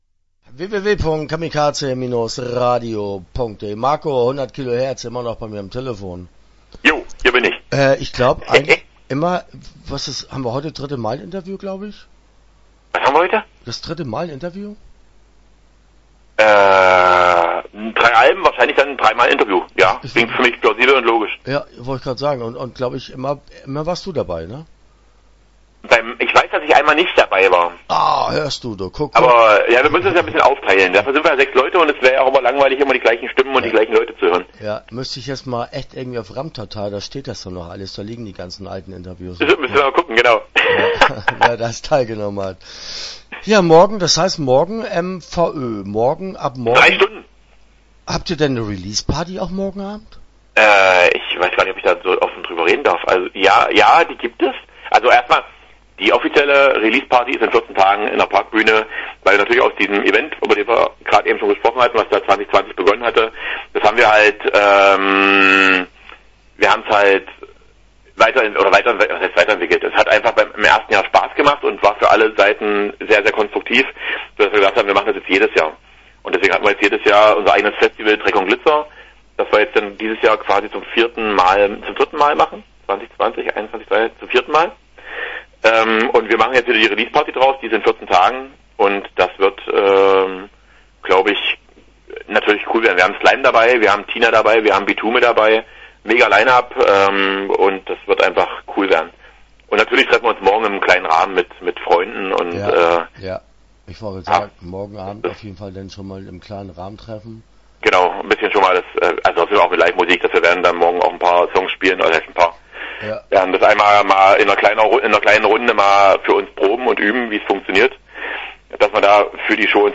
100 Kilo Herz - Interview Teil 1 (10:59)